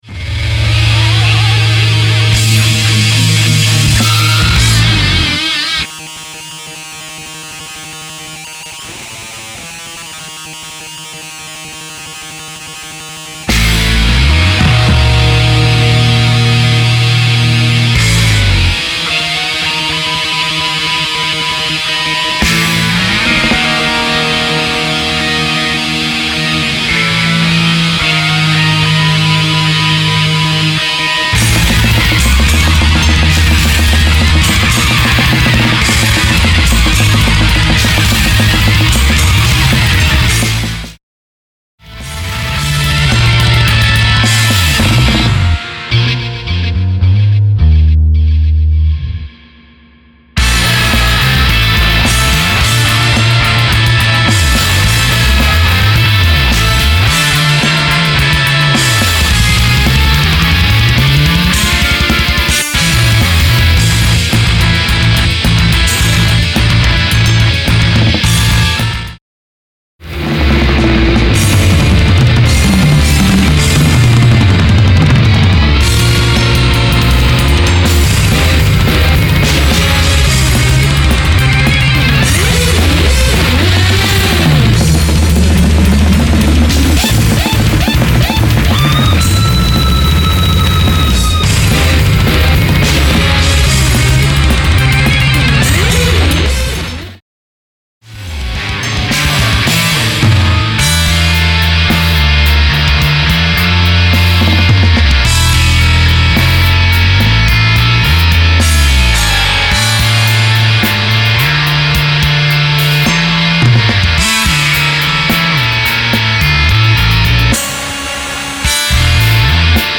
Австрийская блэк-метал-формация